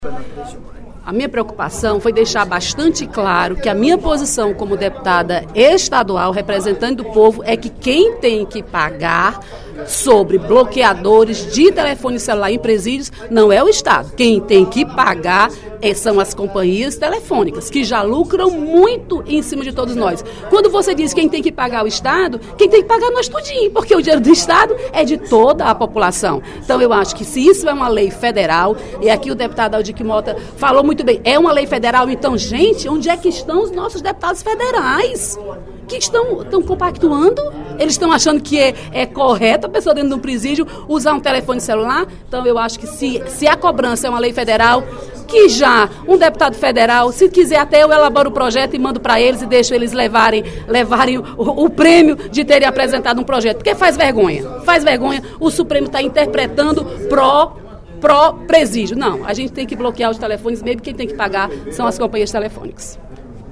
A deputada Dra. Silvana (PMDB) defendeu, durante o primeiro expediente da sessão plenária desta sexta-feira (26/02), a mensagem do Governo do Estado que bloqueia sinais de celulares dentro dos presídios cearenses.